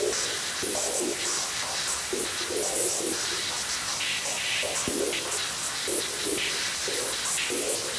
Index of /musicradar/stereo-toolkit-samples/Tempo Loops/120bpm
STK_MovingNoiseD-120_03.wav